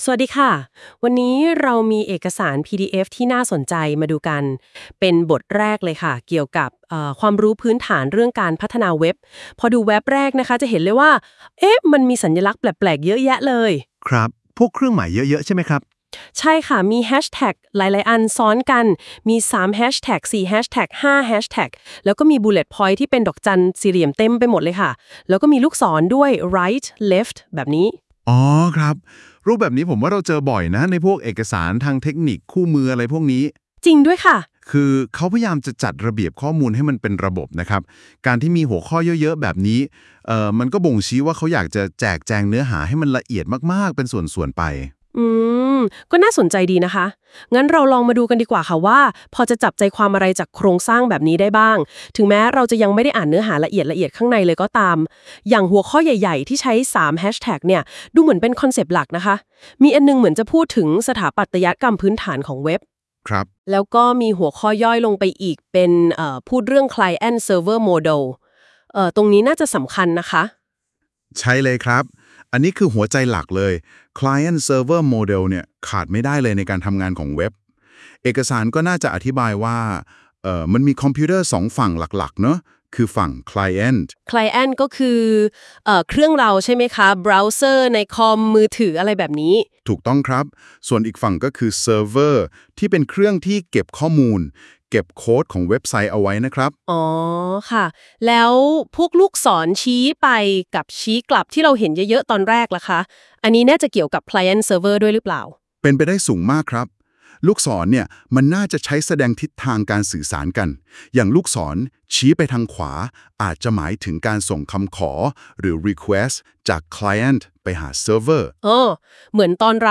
Lec_Basic_Web_Development_and_Programming_Knowledge.wav